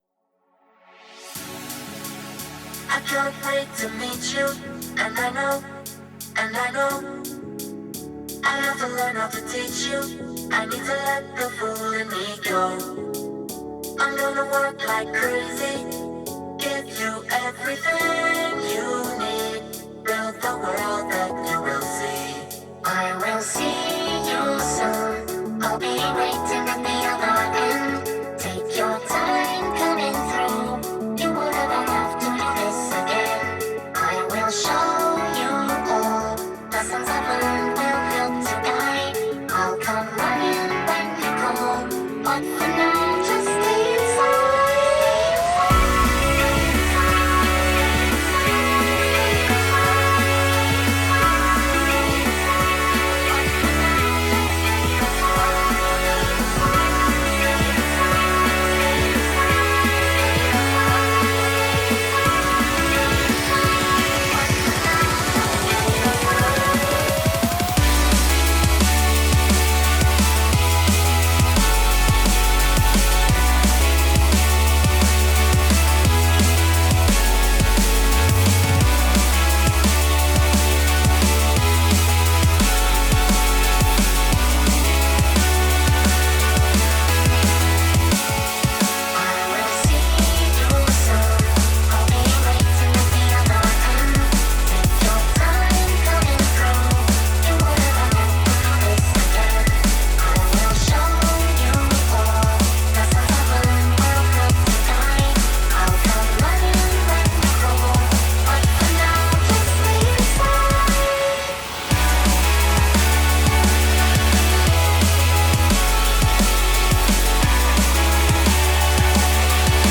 This drum & bass mix